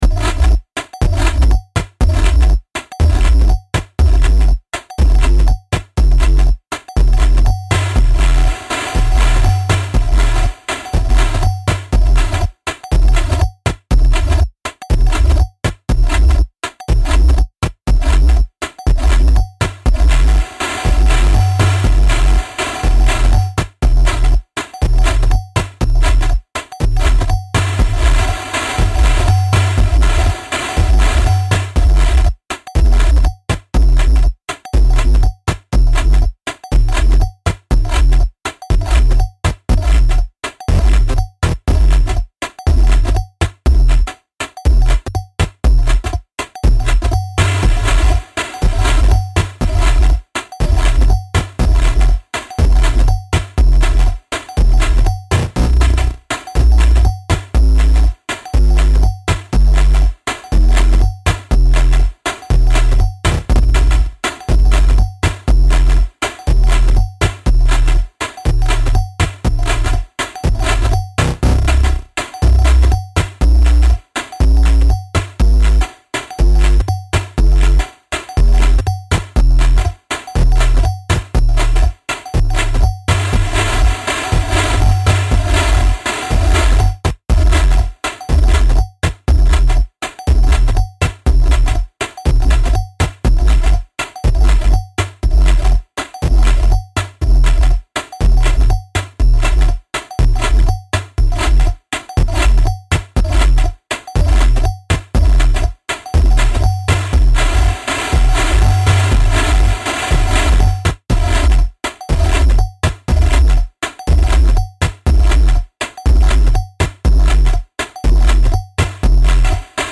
This has a tendency of psychedelically twisting the underlying beat.
• Use a Control All machine (or function-knob twists) to mangle the synthesis parameters, and optionally the insert effects to mess things up.
Sounds industrial and other worldly.
Wandering and non-predictable…
this beat’s got some junk in the trunk — mostly crinkled foil and other bouncy static treets